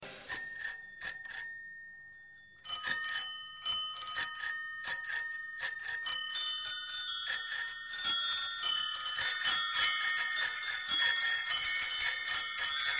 bicycle-bell_25286.mp3